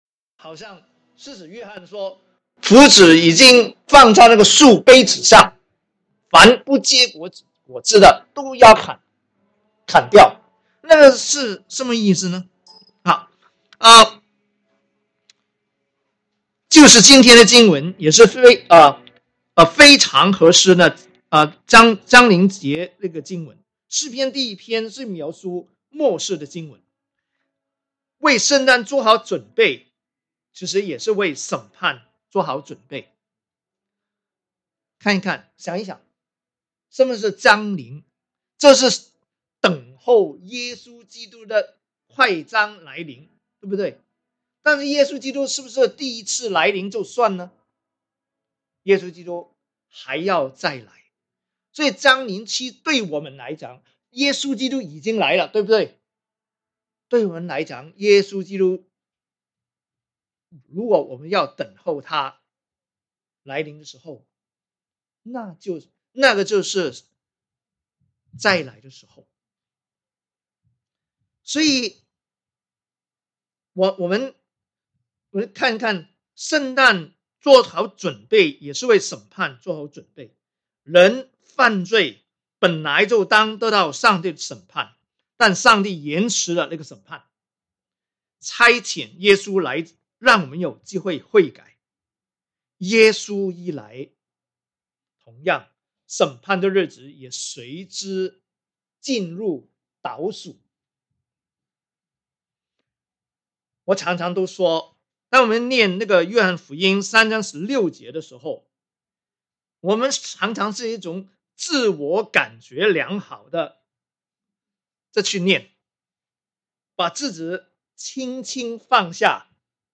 Sermons | 基督教主恩堂